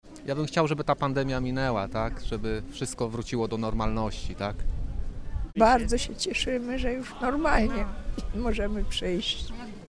Święcenie pokarmów w katedrze wrocławskiej
– Dobrze, że już wszystko wraca pomału do normalności – mówili wrocławianie.